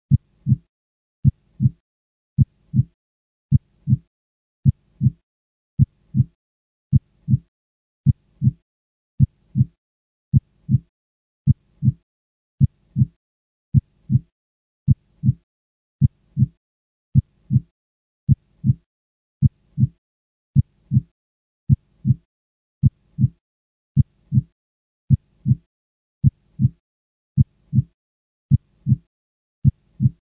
جلوه های صوتی
دانلود صدای ضربان قلب ریتم دار از ساعد نیوز با لینک مستقیم و کیفیت بالا
برچسب: دانلود آهنگ های افکت صوتی انسان و موجودات زنده دانلود آلبوم صدای ضربان قلب انسان از افکت صوتی انسان و موجودات زنده